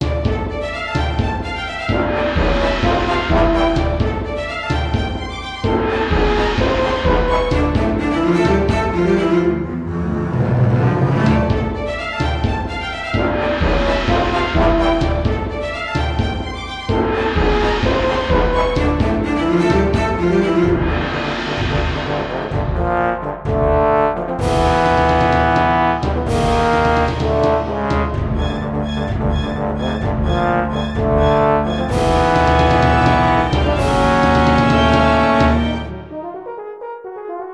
高音ストリングスが奏でる鋭く緊張感高まる旋律に、低音ブラスのおどろおどろしい旋律が続きまさ。
ショートループ